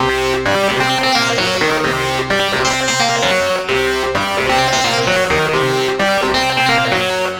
CRYINGBRASSA 2.wav